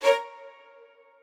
strings4_32.ogg